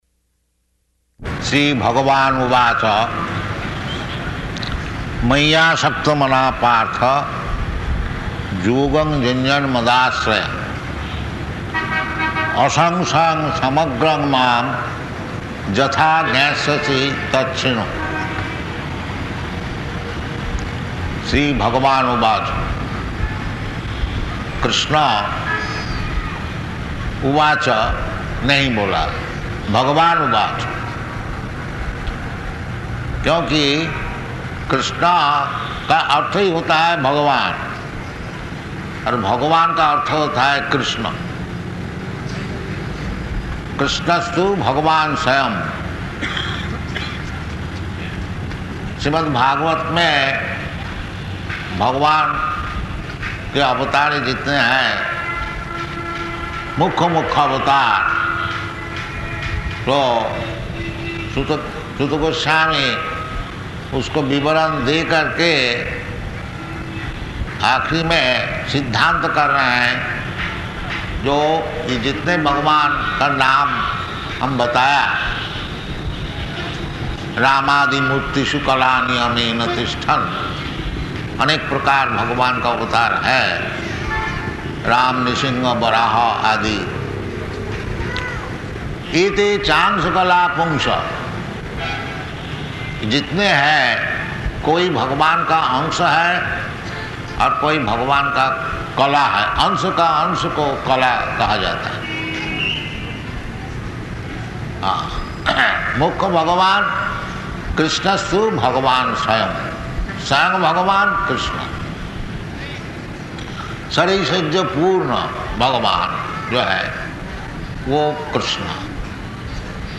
Type: Bhagavad-gita
Location: Ahmedabad